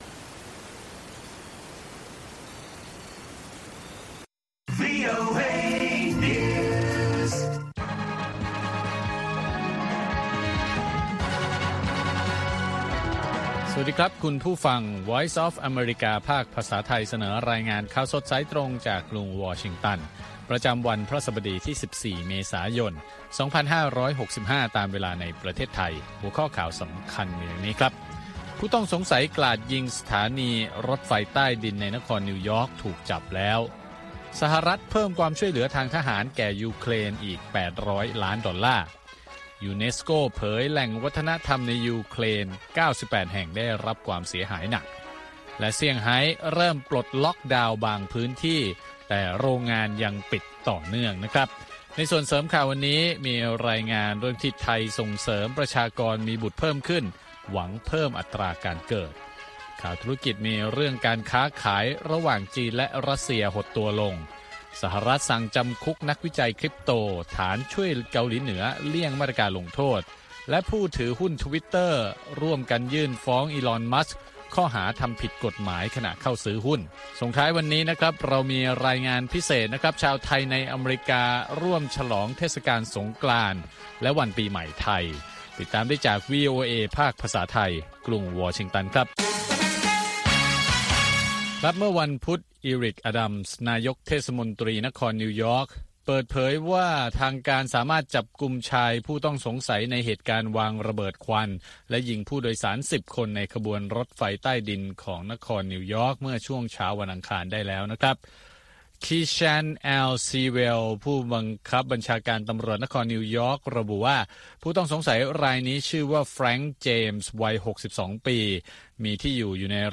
ข่าวสดสายตรงจากวีโอเอ ภาคภาษาไทย 6:30 – 7:00 น. ประจำวันพฤหัสบดีที่ 14 เมษายน 2565 ตามเวลาในประเทศไทย